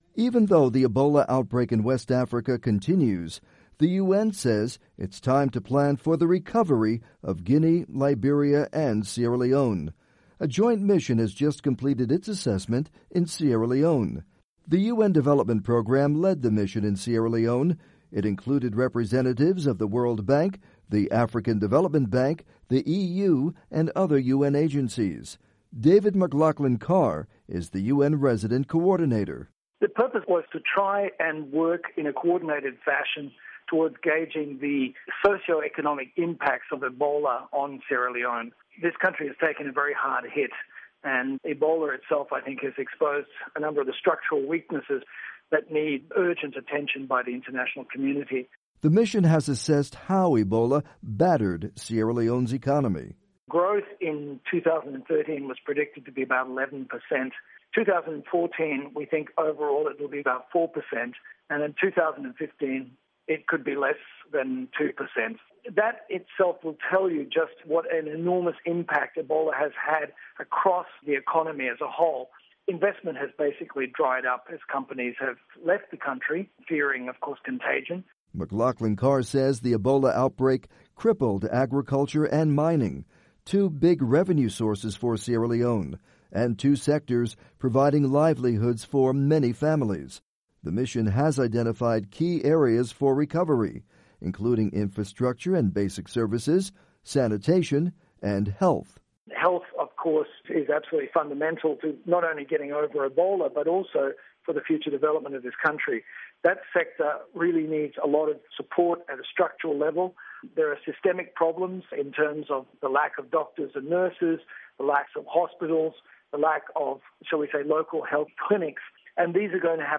report on Ebola recovery plans